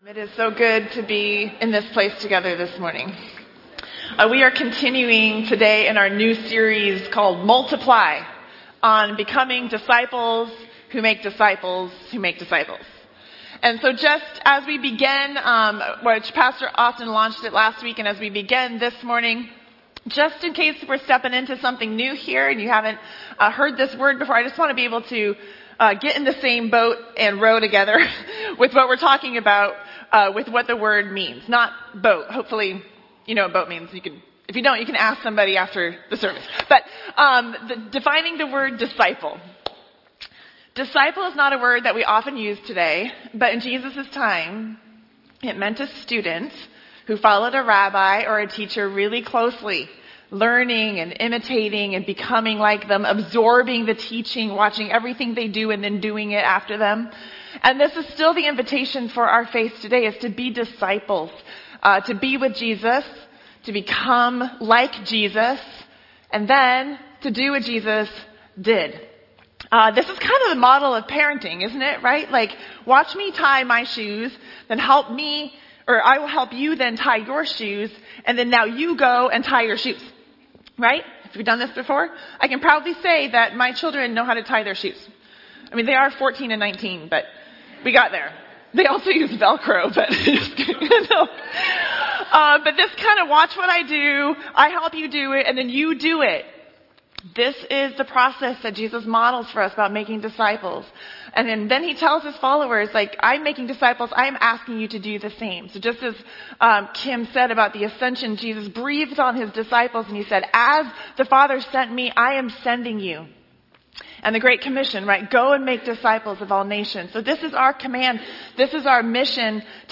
Last week we launched our new sermon series, “Multiply,” examining Jesus’ call to make disciples.